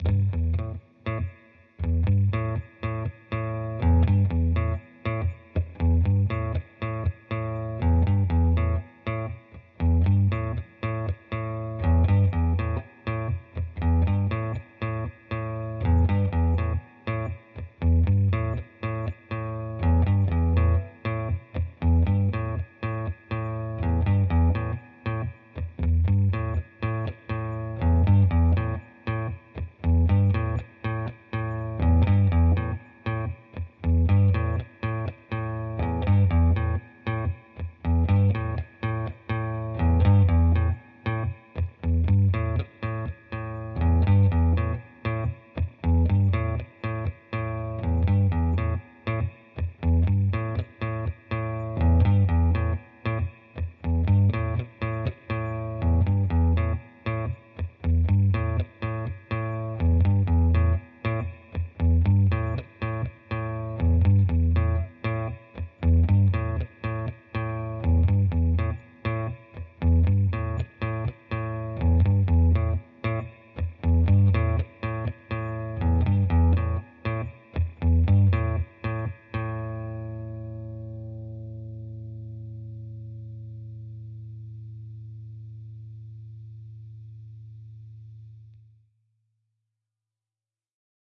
Funky Tuna Guitar (120 Bpm) in Am
描述：使用放大器建模清洁Logic Pro中的Funk Electric GuitarRecord
Tag: 放克 干净 吉他 AM 120BPM